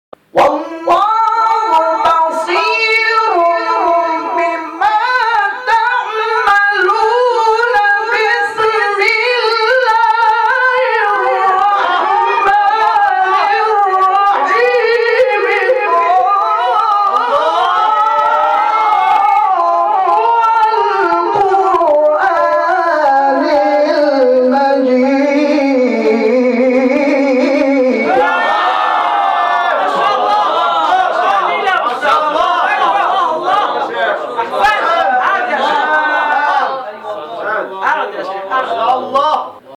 گروه شبکه‌های اجتماعی: فرازهای صوتی از تلاوت قاریان ممتاز و بین المللی کشور که در شبکه‌های اجتماعی منتشر شده است، می‌شنوید.